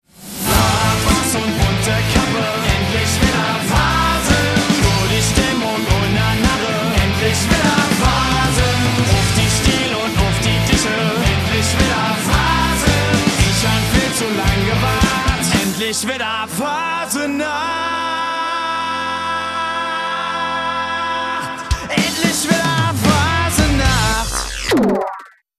saarländische Faasenachtsrockband